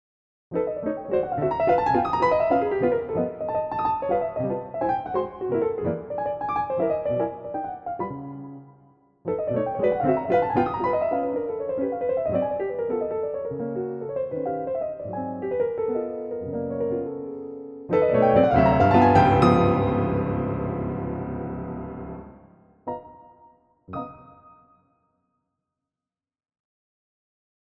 Pianoteq 8: Grotrian Concert Royal (modified)